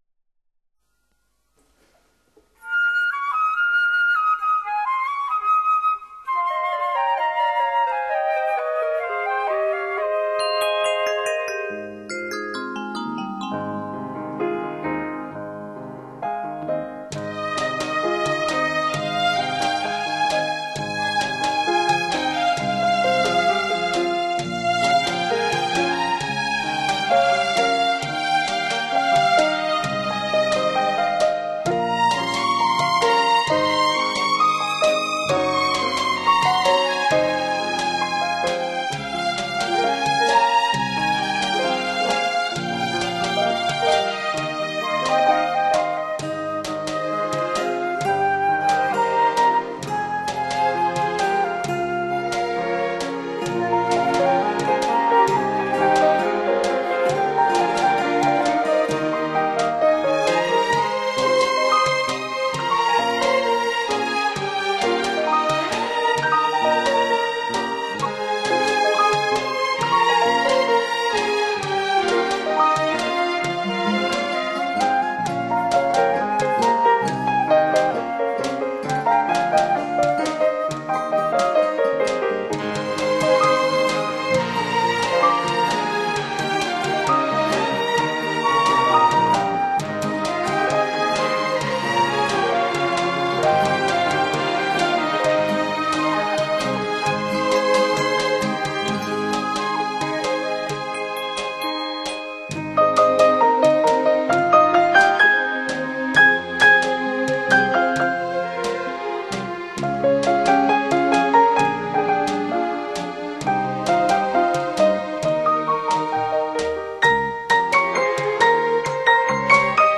浪漫民族钢琴小品